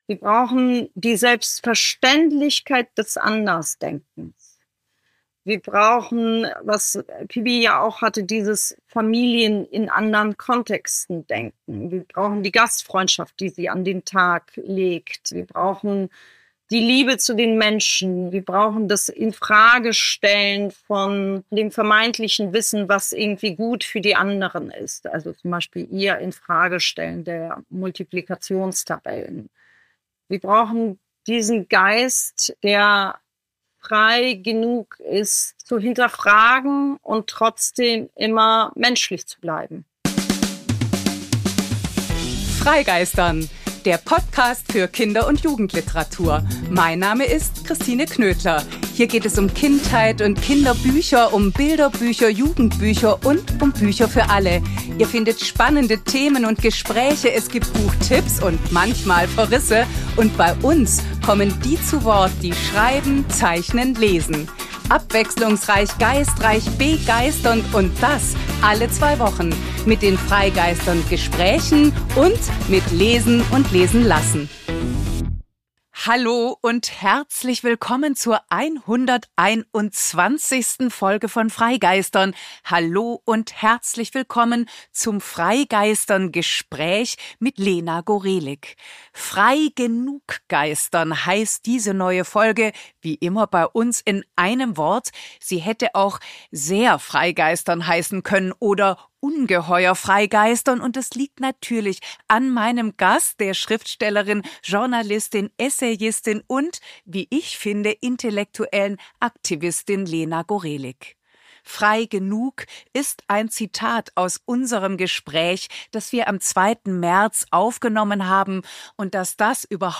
Man könnte sie eine intellektuelle Aktivistin nennen: eine, die sich klar positioniert zu aktuellen gesellschaftlichen Entwicklungen und dabei immer auf Offenheit und Dialog setzt. Am 13. März 2026 erscheint bei Rowohlt ihr neuester Roman - die 121. freigeistern!-Folge könnte also aktueller nicht sein: ein Gespräch über „Alle unsere Mütter“, Mütter, Nicht-Mütter, Frausein, über die Jugendliteratur und Literatur als Möglichkeit des Aushandelns und vielleicht der Veränderung, über Theater und Literaturhäuser als Orte der Auseinandersetzung und zugleich Begegnung, über Erinnern, Erinnerungskultur und immer über das Schreiben von Lena Gorelik.